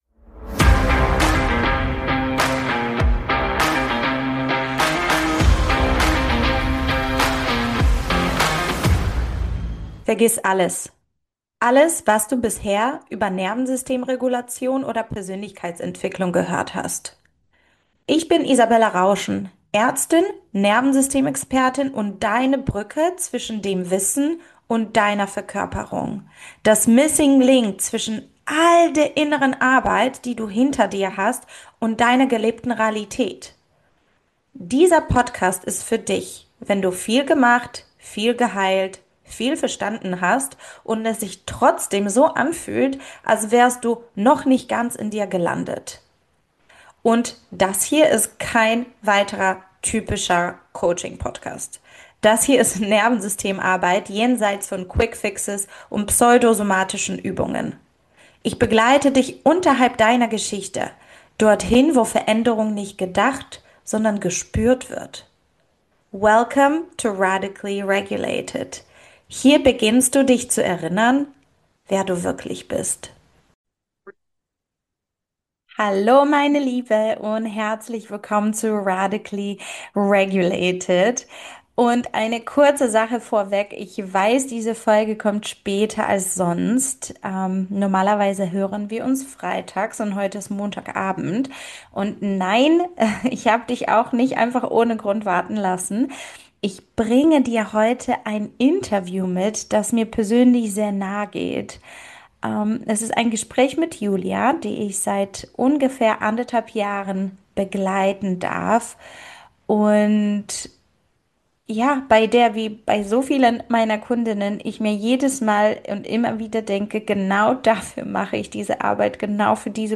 Was bringt eine Frau zur Nervensystemarbeit, wenn im Außen alles passt? Ein persönliches Gespräch über Funktionieren, Hustle und Sicherheitsnetze – und darüber, was sich verändert, wenn innere Sicherheit nicht mehr über Leistung hergestellt wird.